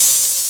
edm-hihat-13.wav